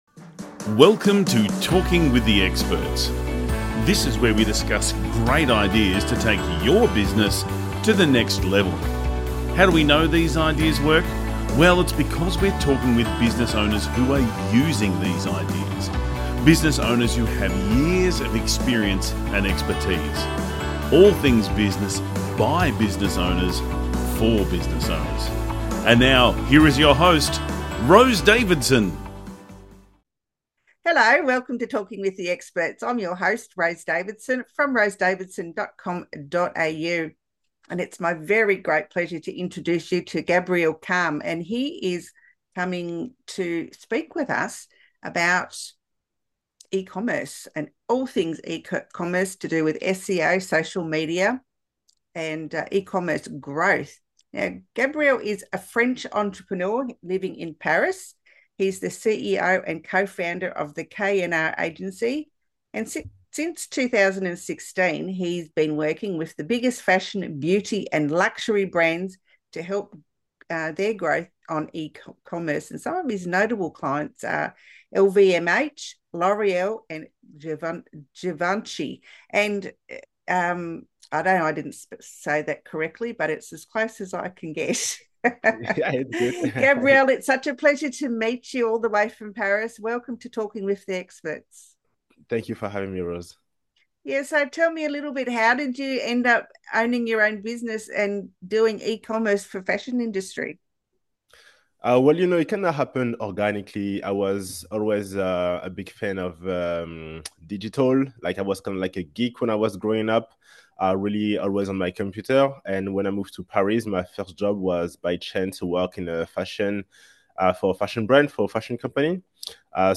💡 Three Key Points from the Interview: